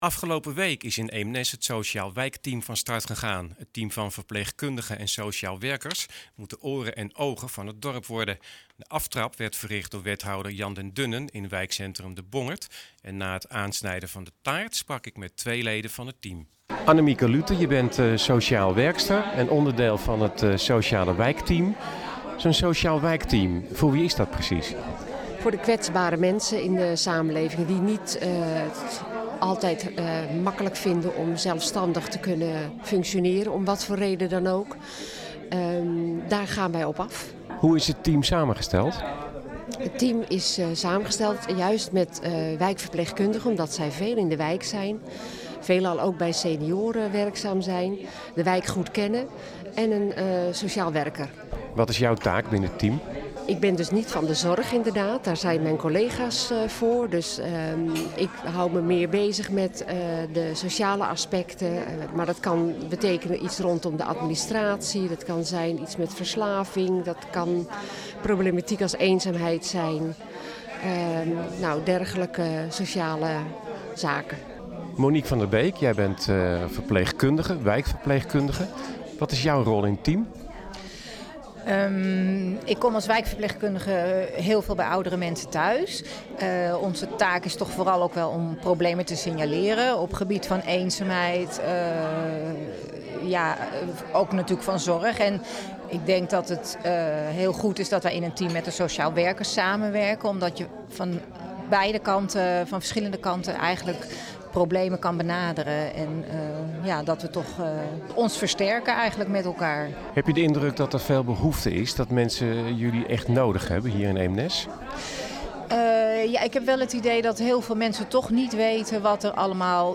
In Eemnes is afgelopen week het startsein gegeven voor het Sociaal Wijkteam. Het team moet de oren en ogen van het dorp worden. Twee leden van het team aan het woord.